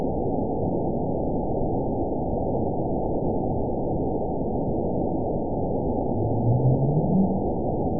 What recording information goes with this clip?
event 919831 date 01/25/24 time 15:35:34 GMT (1 year, 10 months ago) score 9.41 location TSS-AB06 detected by nrw target species NRW annotations +NRW Spectrogram: Frequency (kHz) vs. Time (s) audio not available .wav